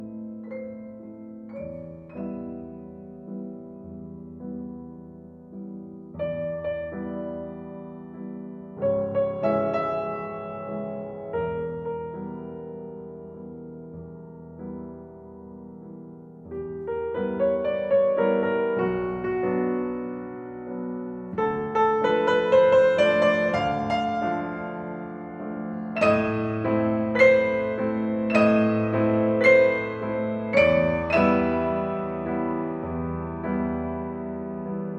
Classical Piano
Жанр: Классика